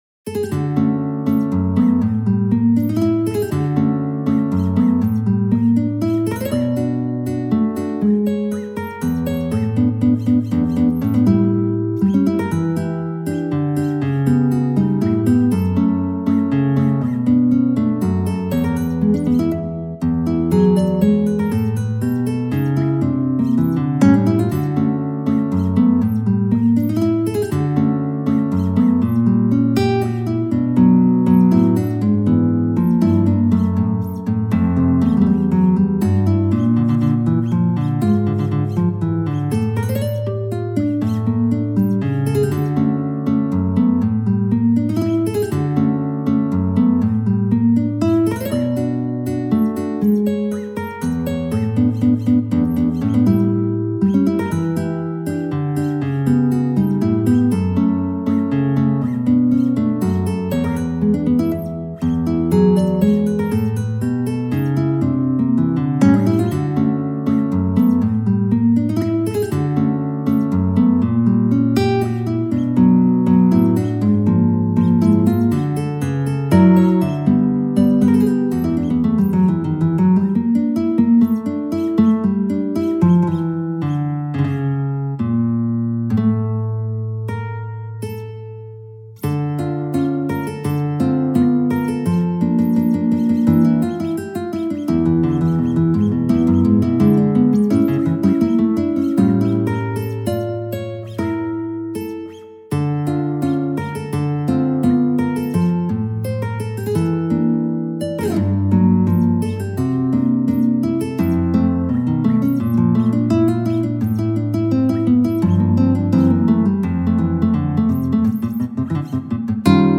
(Guitar Solo)